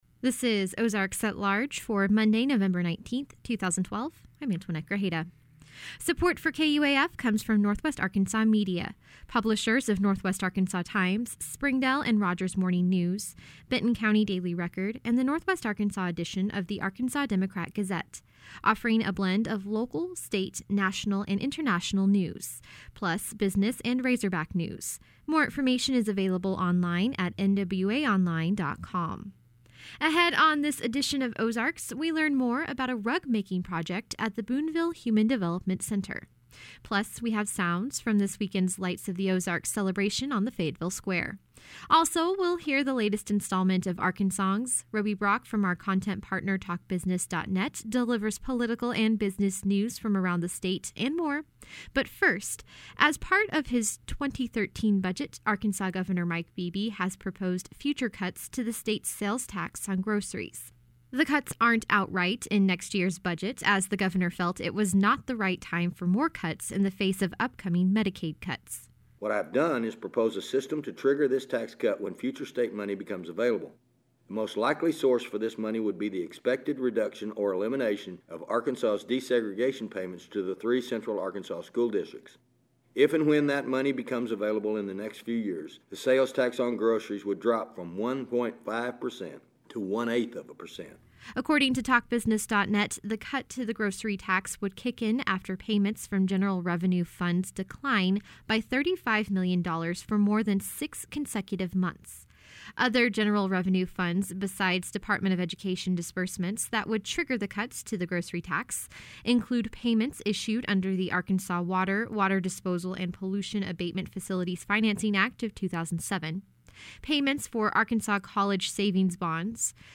Audio: oalweb111912.mp3 On this edition of Ozarks, we learn more about a rug-making project at the Booneville Human Development Center. Plus, we have sounds from this weekend's Lights of the Ozarks celebration on the Fayetteville Square.